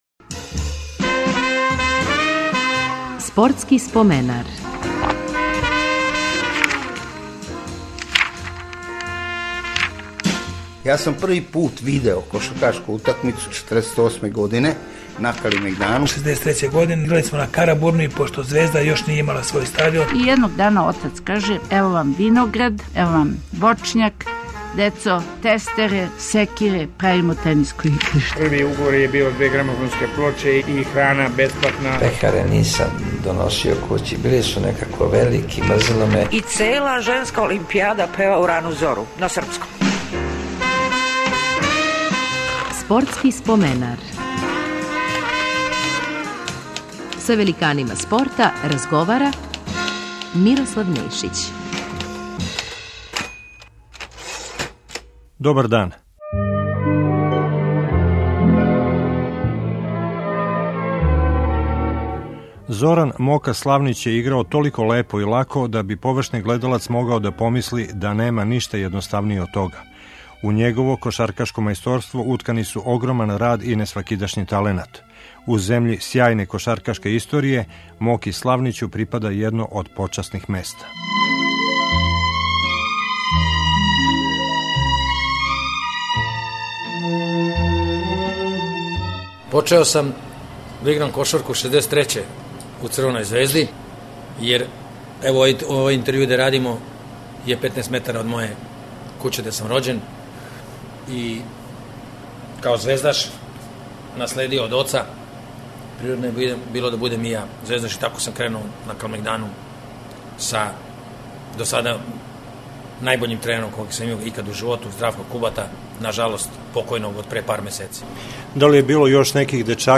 Репризирамо емисију у којој нам је гост био кошаркаш Зоран-Мока Славнић, својевремено један од најбољих светских бекова.